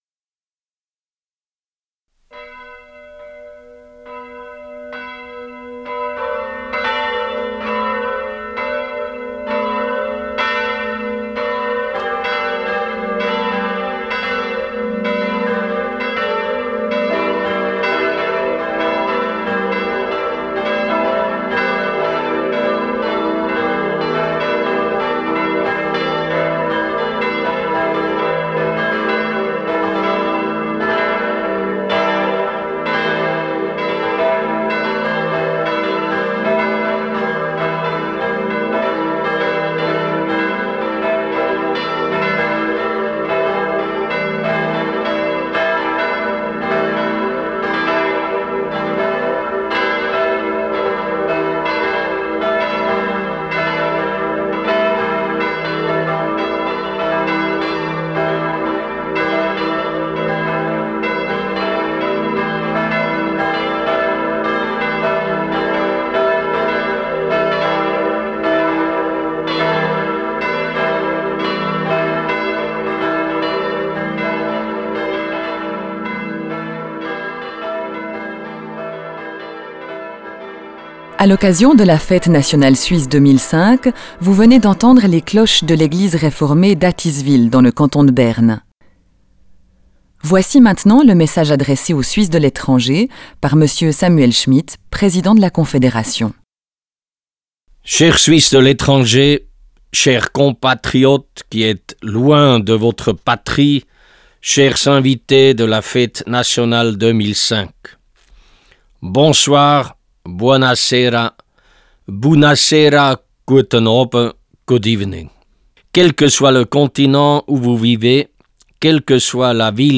Allocution à l’intention des Suisses et des Suissesses de l’étranger prononcée par Samuel Schmid, président de la Confédération, le 1er août 2005, à l’occasion de la fête nationale.